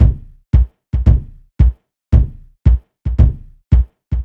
Lastly, Timbaland generally will use a lower timbre bass drum to carry the rhythm and act as a phantom kick.
It’s also a technique used by real drummers for decades and adds a more organic, human vibe to the groove.
Bass_Drum_Example_3.mp3